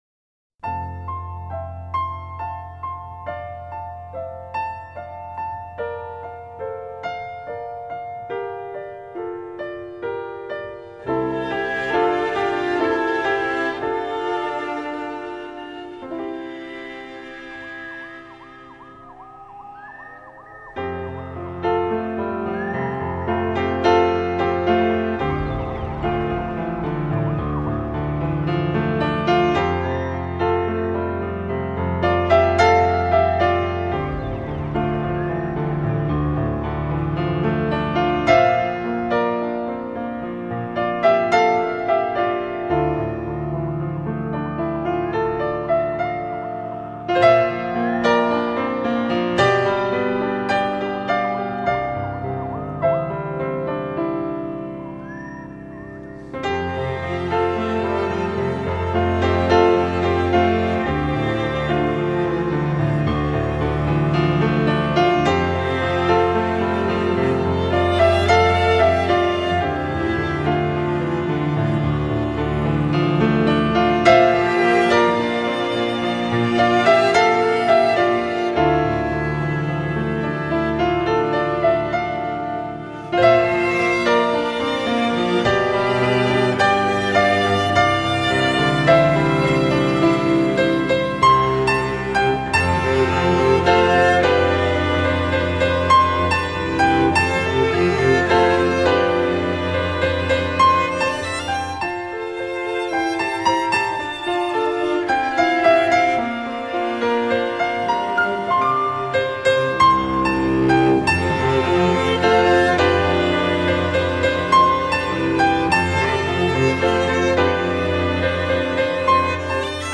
专辑类型：钢琴
流畅静谧的钢琴音符《推荐您独处时聆赏》
记录钢琴与心灵的对话，采自德国黑森林的钟响、微风、鸟鸣等，特别适合在静谧午夜时独自品味！